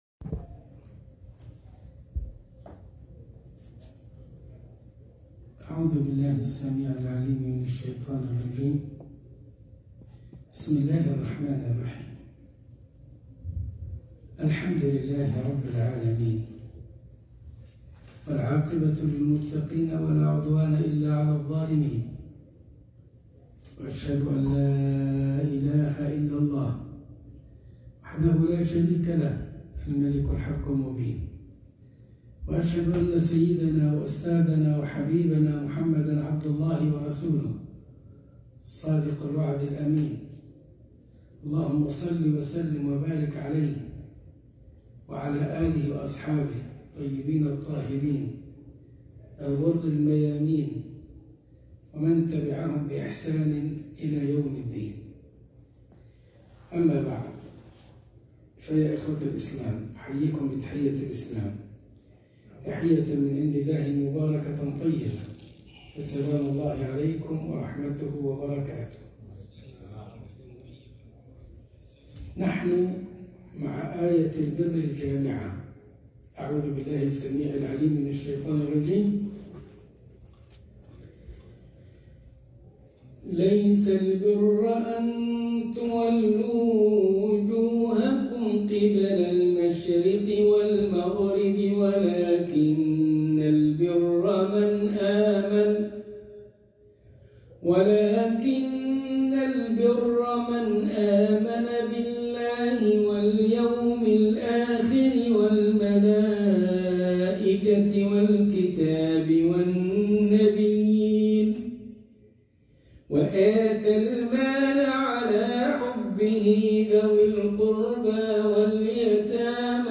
خاطرة المغرب من مسجد الدار، تورنتوكندا ( آية البر الجامعة )2